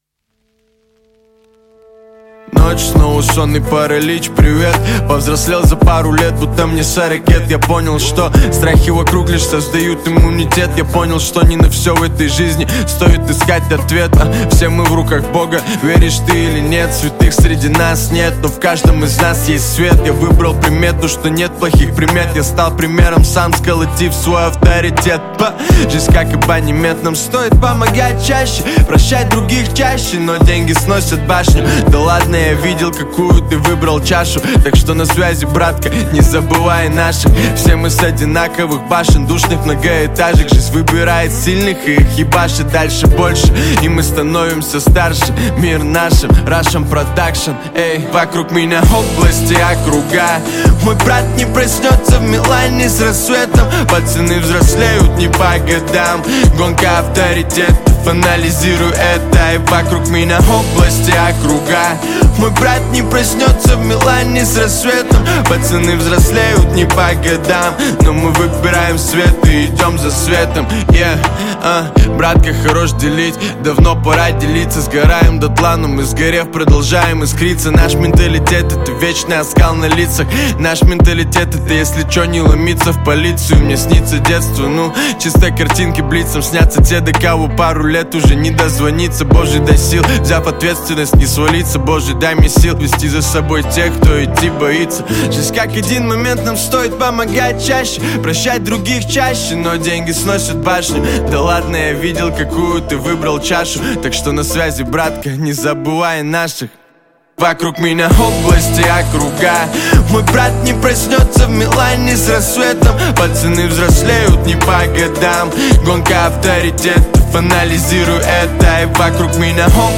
Хип-хоп
Жанр: Жанры / Хип-хоп